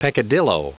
Prononciation du mot peccadillo en anglais (fichier audio)
Prononciation du mot : peccadillo